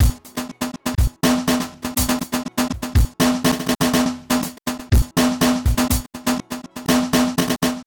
DrumLoop02.wav